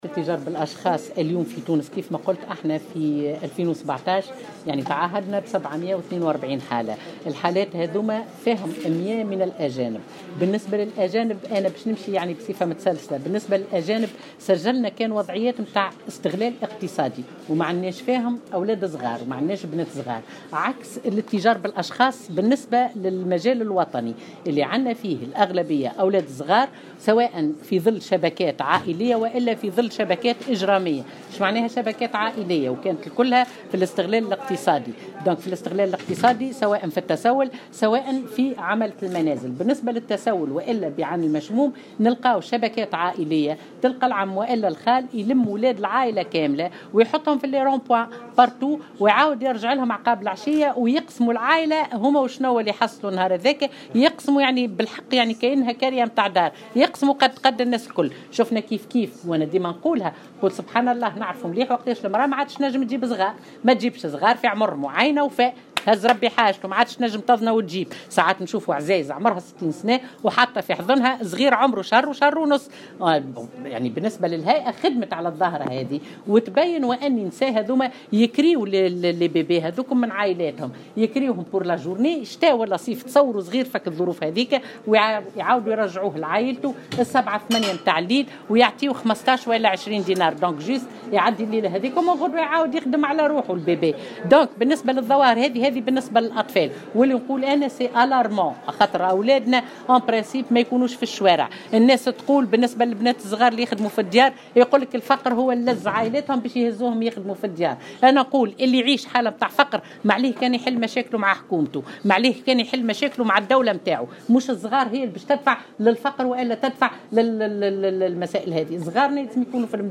أكدت رئيسة الهيئة الوطنية لمكافحة الاتجار بالاشخاص روضة العبيدي في تصريح لمراسل الجوهرة 'اف ام" اليوم الإثنين أن وزارتها تعهدت ب742 حالة اتجار بالأشخاص في سنة 2017 بينها 100 حالة من الأجانب.